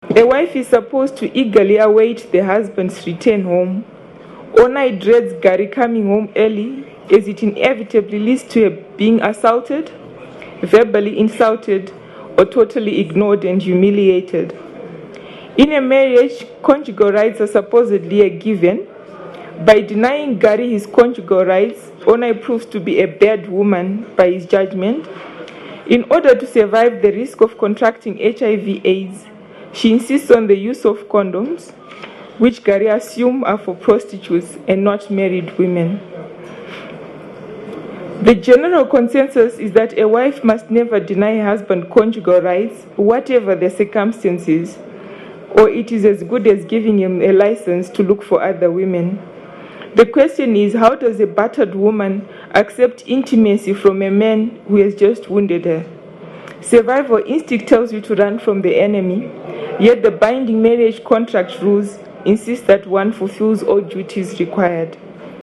These and other questions debated on 18 September 2008, when a small crowd gathered at Harare's Book Café to discuss Valerie Tagwira's 2007 novel, The Uncertainty of Hope, published by Weaver Press.